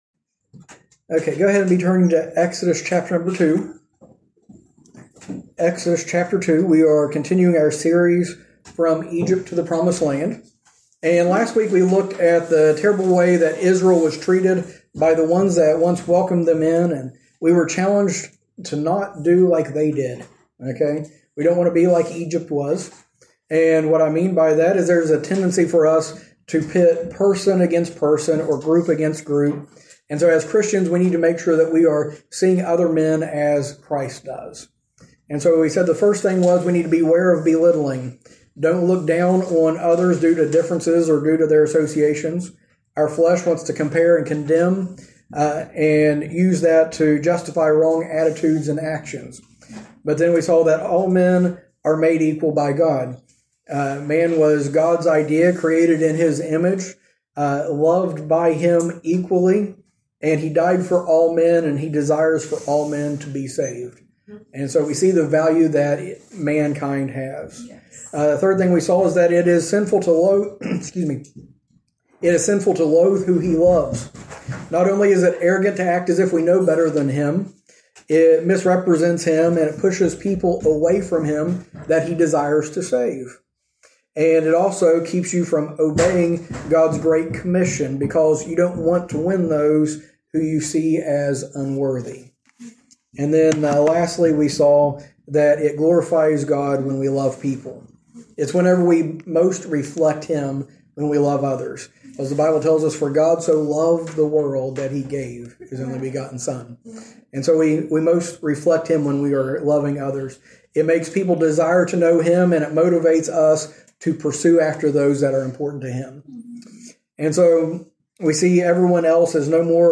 From Series: "Recent Sermons"